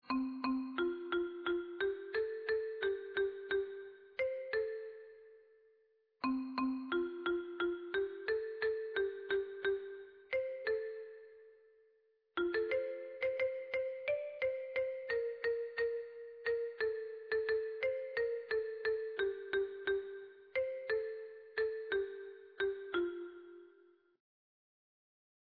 Musik: Traditionelles Volkslied